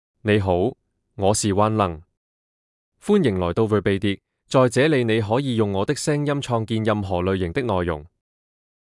MaleChinese (Cantonese, Traditional)
WanLungMale Chinese AI voice
WanLung is a male AI voice for Chinese (Cantonese, Traditional).
Voice sample
WanLung delivers clear pronunciation with authentic Cantonese, Traditional Chinese intonation, making your content sound professionally produced.